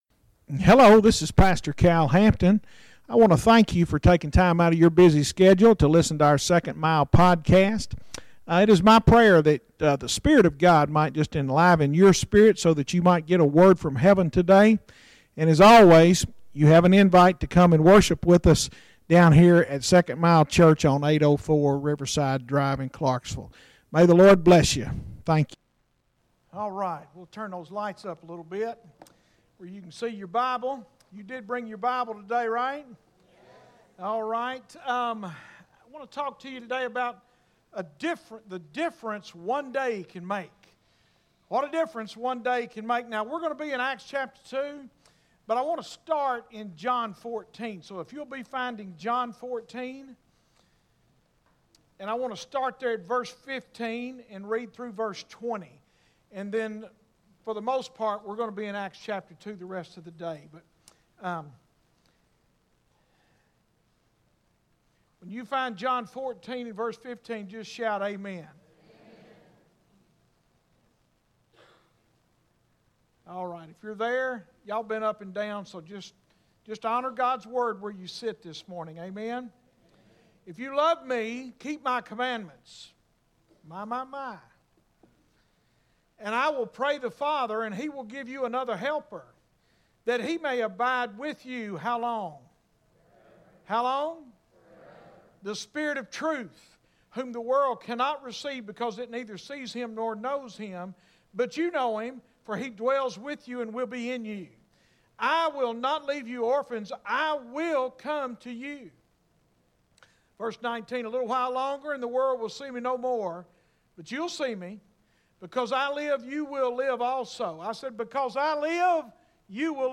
Sermons Archive - 2nd Mile Church